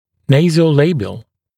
[ˌneɪzəu’leɪbɪəl][ˌнэйзоу’лэйбиэл]носогубный, назо-лабиальный